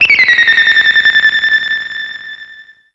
Play, download and share Gundam warning signal original sound button!!!!
gundam-warning-signal.mp3